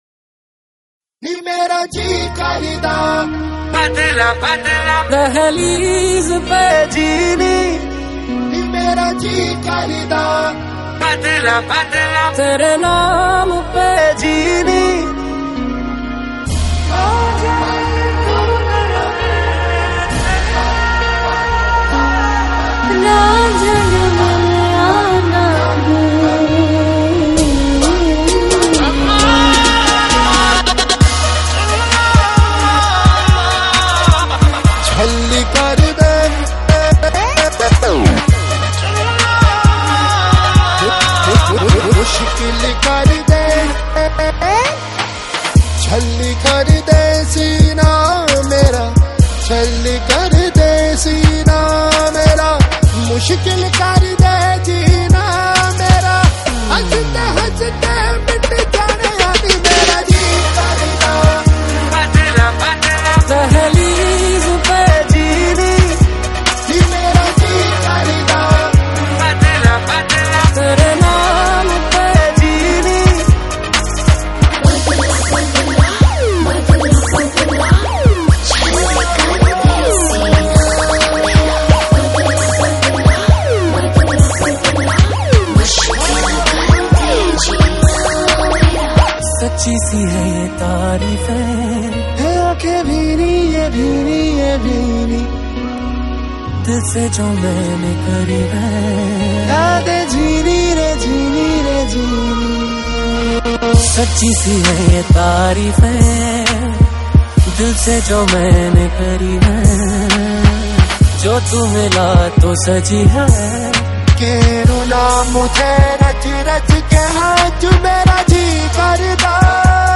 Bollywood Mp3 Music 2015